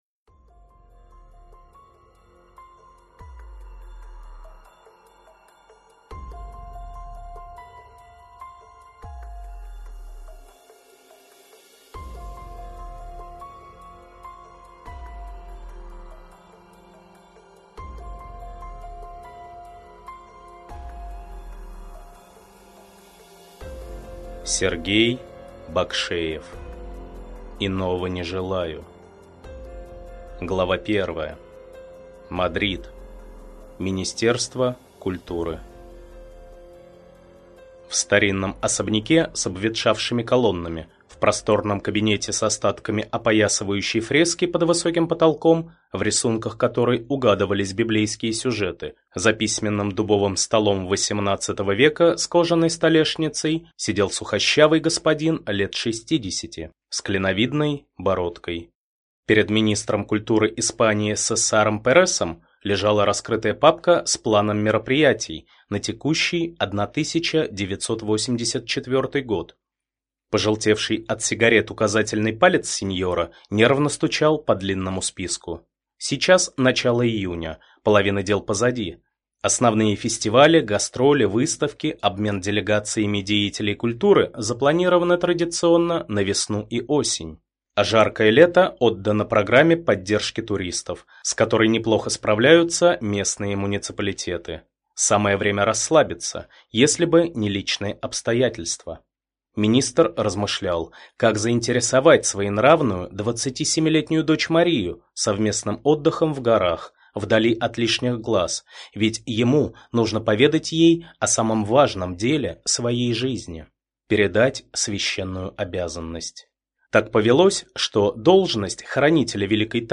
Аудиокнига Иного не желаю | Библиотека аудиокниг